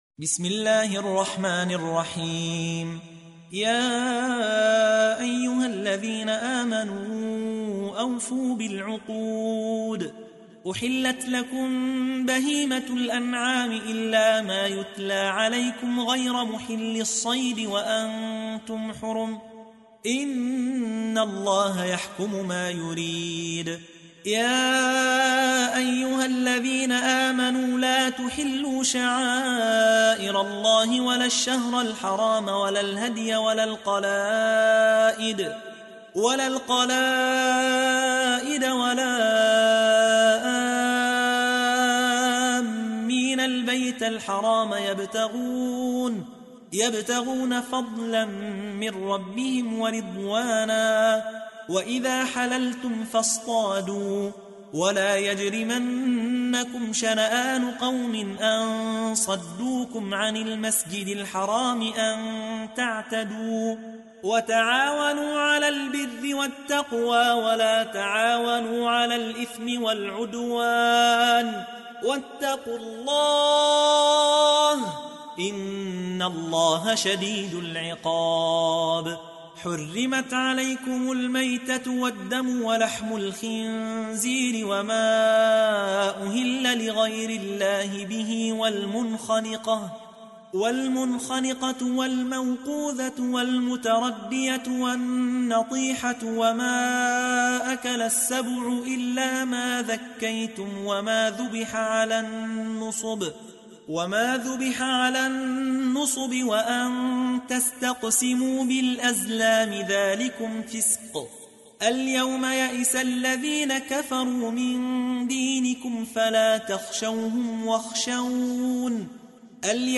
تحميل : 5. سورة المائدة / القارئ يحيى حوا / القرآن الكريم / موقع يا حسين